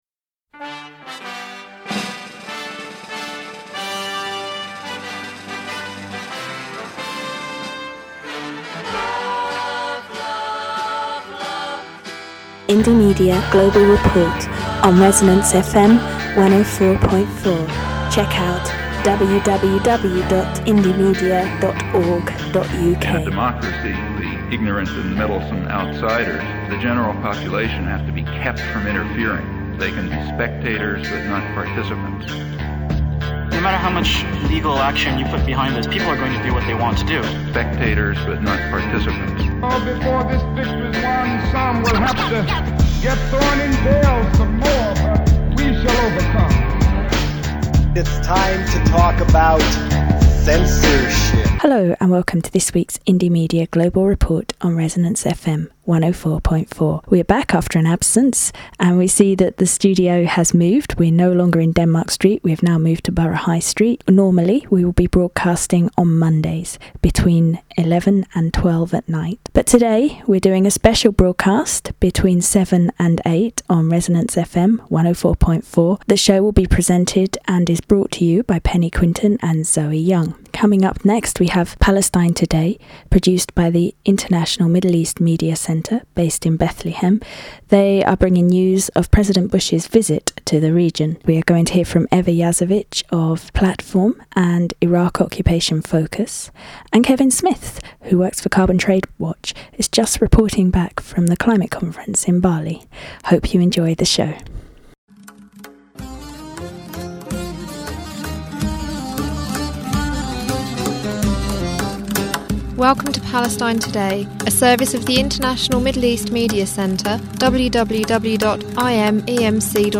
Indy Global Reports is back on ResonanceFM104.4. Indy Global Reports returned to London's air waves on Thursday 10th of Jan at 7pm for a special broadcast before resuming it's regular slot of 11.00pm till 12.00pm every Monday evening .